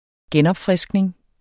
Udtale [ ˈgεnʌbfʁεsgneŋ ]